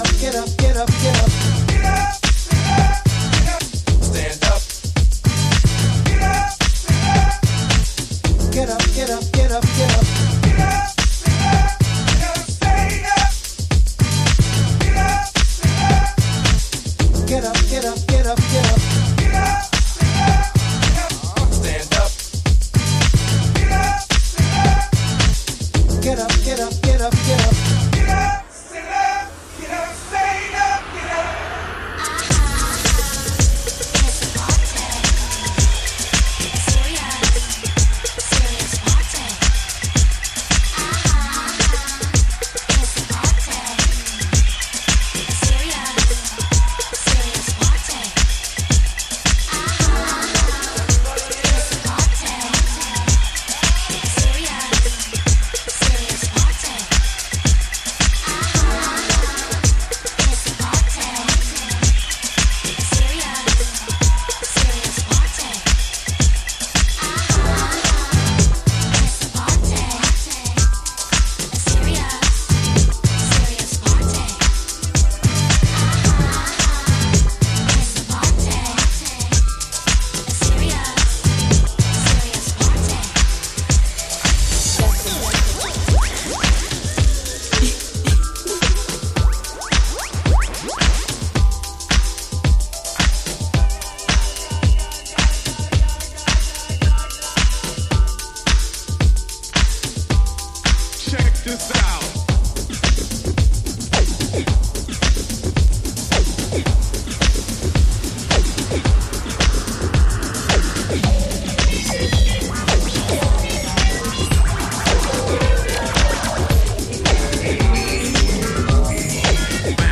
Alt Disco / Boogie
ヘヴィーディスコグルーヴ。